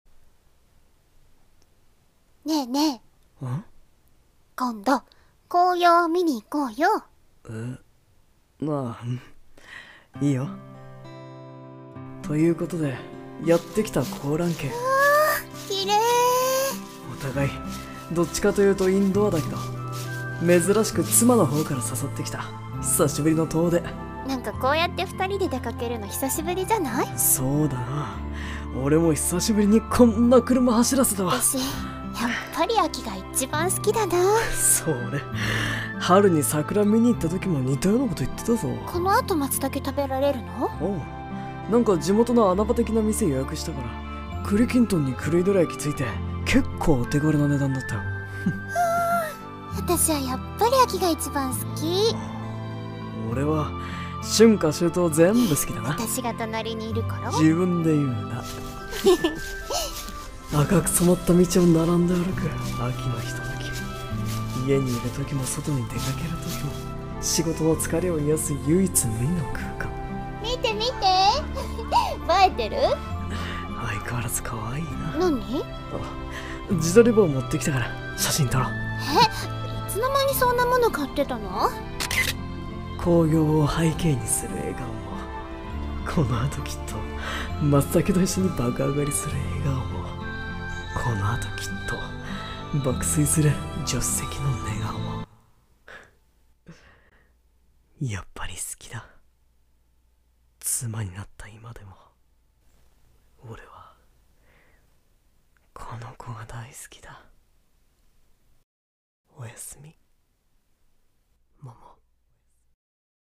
【２人声劇】紅葉狩り (♂1：♀1)